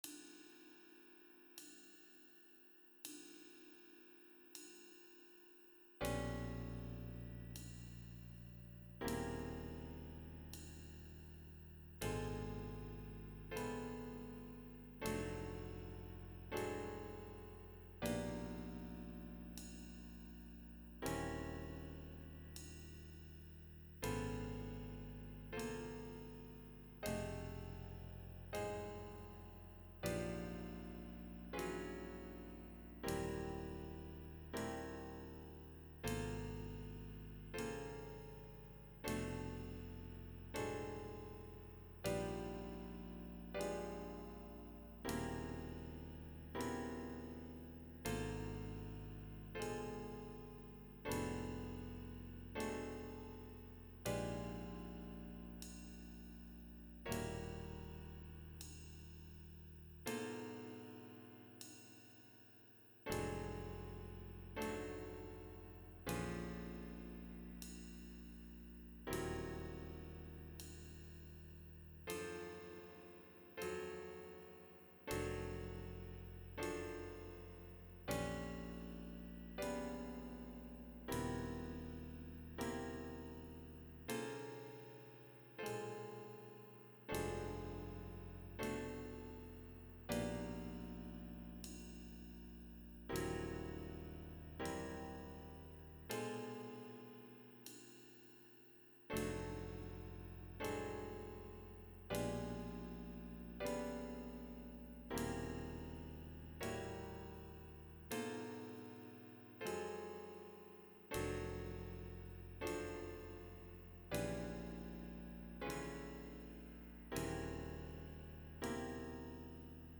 MP3 with Bass and Cymbal